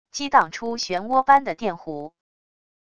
激荡出漩涡般的电弧wav音频